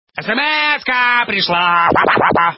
Список файлов рубрики Для СМС